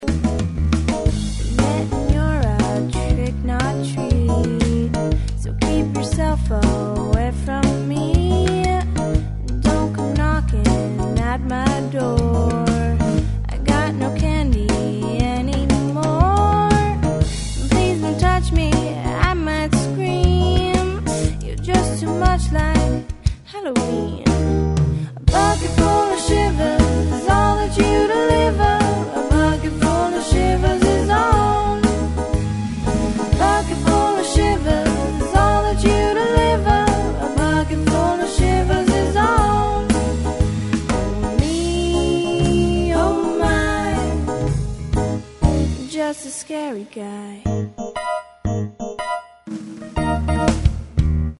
highly amusing anti bullying empowerment tune